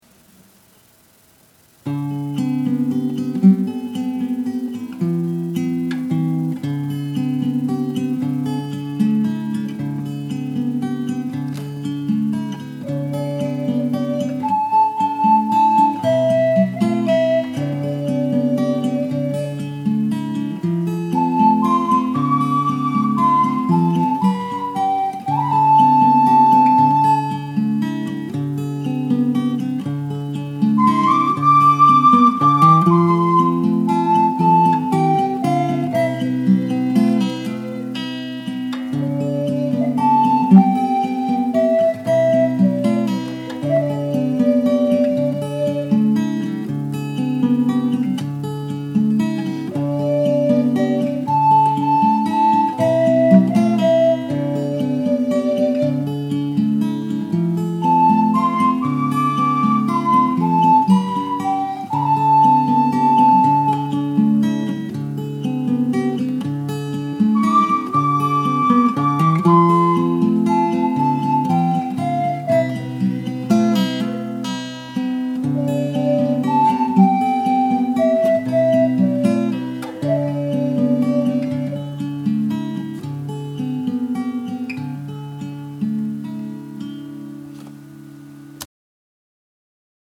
Scarborough Fair: Guitar and Ocarina
The first audio clip is the original recording, and the second one has a voice recording which I added later.
Scarborough_Fair_inst
The first one is better balances.